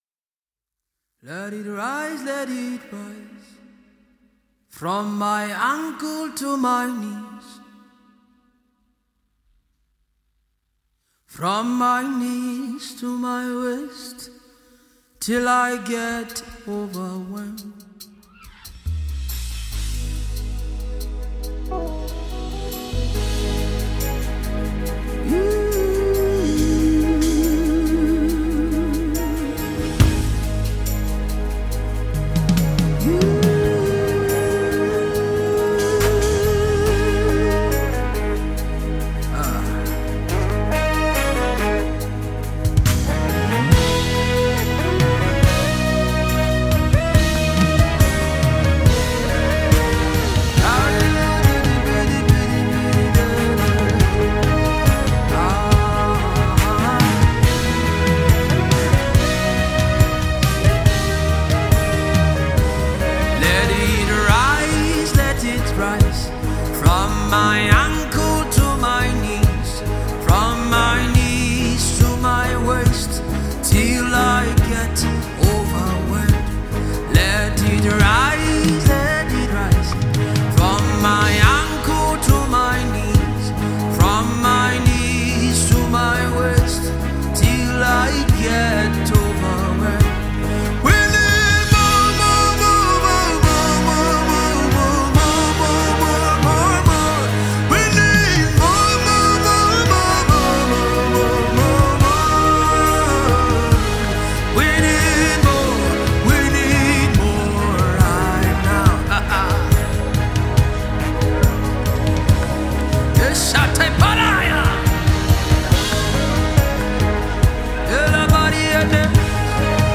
With its upbeat tempo and catchy sounds